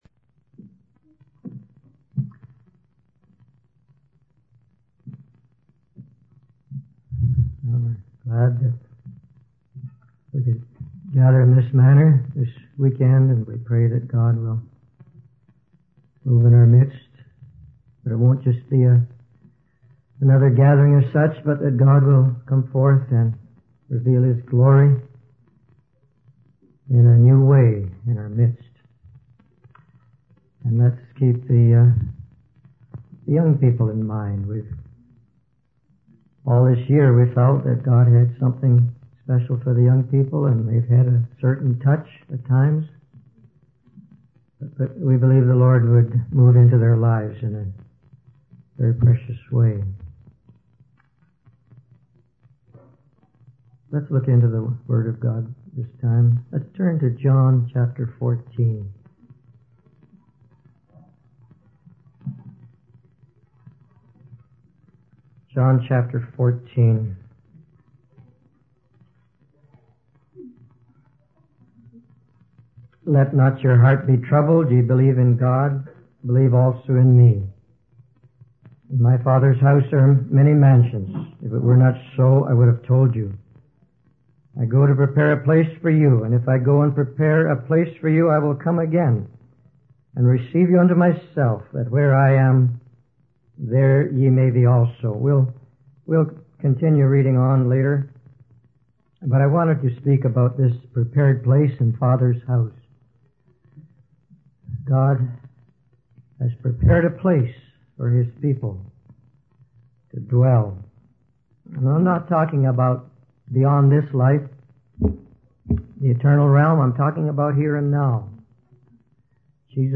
In this sermon, the speaker emphasizes the importance of seeking the Lord earnestly and experiencing His visitation. The speaker shares a personal testimony of going through a difficult time and seeking the Lord's guidance.